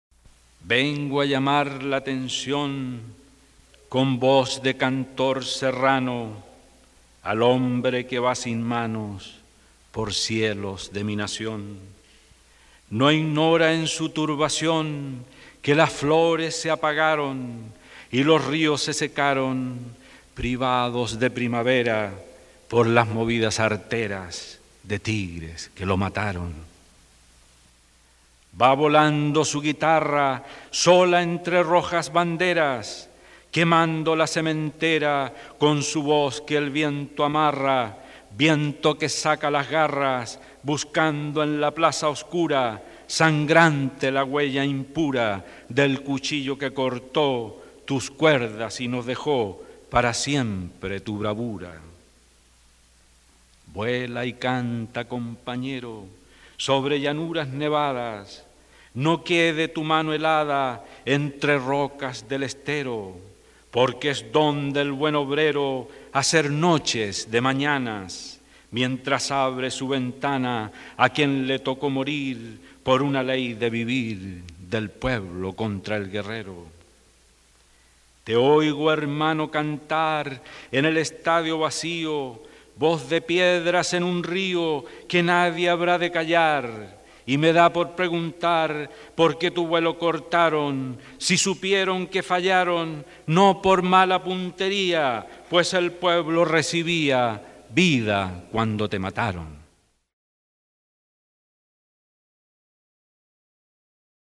Reading his Poetry
Fernando_Alegria_poem.mp3